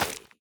Minecraft Version Minecraft Version 1.21.5 Latest Release | Latest Snapshot 1.21.5 / assets / minecraft / sounds / block / roots / break5.ogg Compare With Compare With Latest Release | Latest Snapshot
break5.ogg